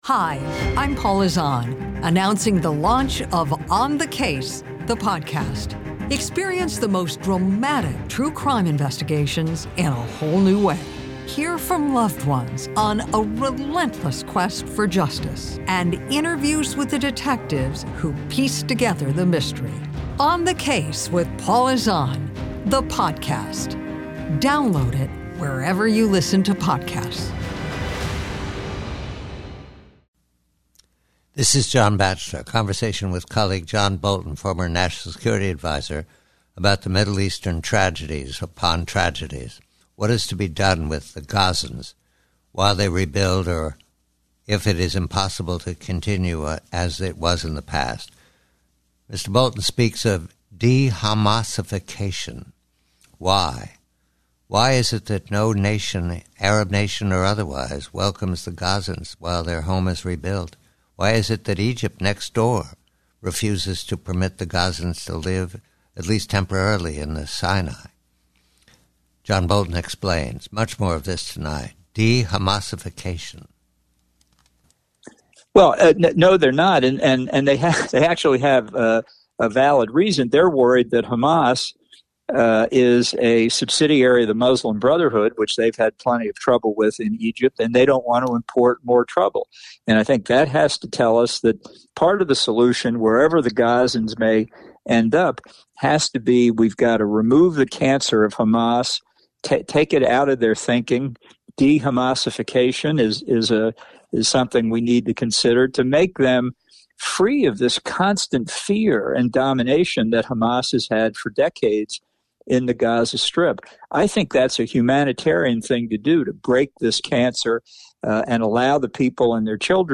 PREVIEW 1: A discussion with John Bolton about Middle Eastern issues, specifically the situation in Gaza and the concept of "DeHamasification". Bolton examines the complex political and military dynamics in the region, exploring potential solutions and challenges facing Israel...